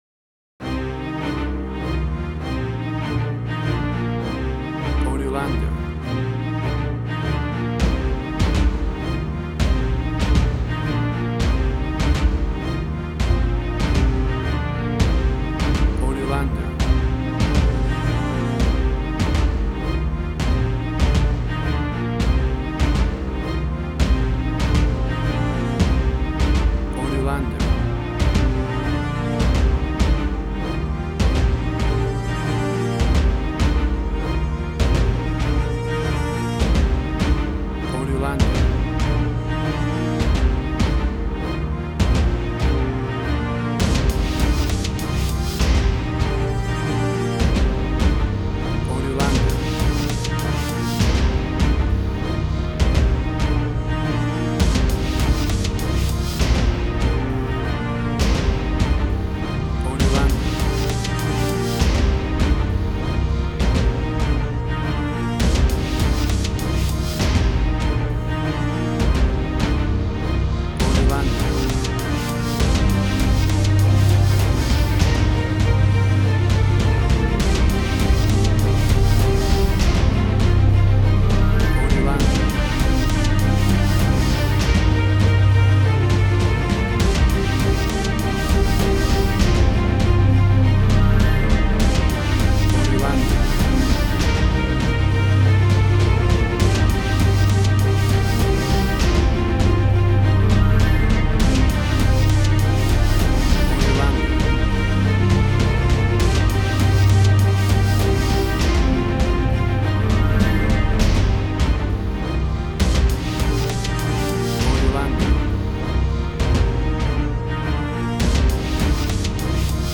WAV Sample Rate: 16-Bit stereo, 44.1 kHz
Tempo (BPM): 101